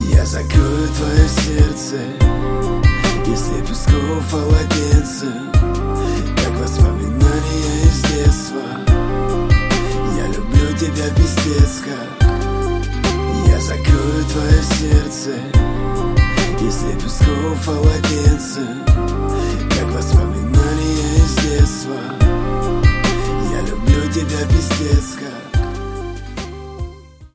• Качество: 128, Stereo
лирика
русский рэп